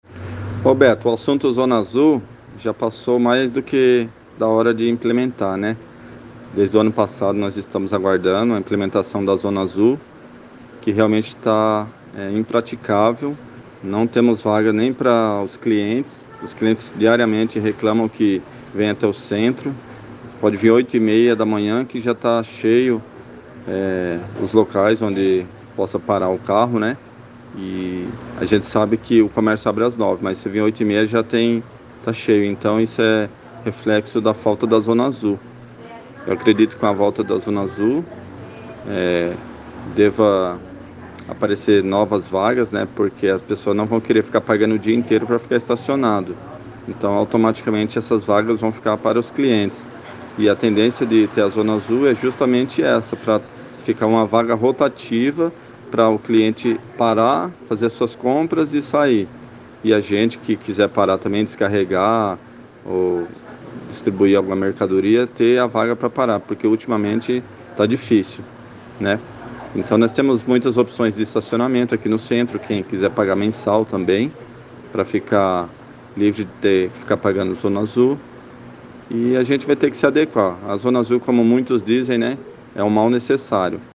Com este novo formato, o pagamento pelo estacionamento deverá ser feito em parquímetros que serão instalados em vários pontos da cidade pela empresa vencedora da licitação, que terá 10 anos de concessão. Sobre o assunto, nossa reportagem falou por telefone com o lojista